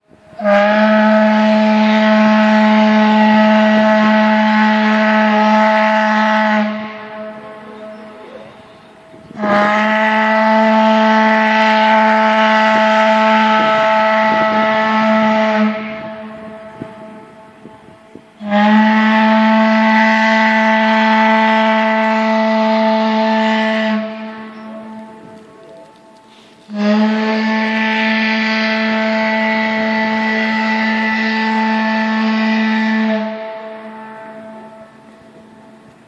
Require Emergency Assistance Horn .mp3 {repeating = verylong-pause}
Require_Emergency_Assistance_Horn.mp3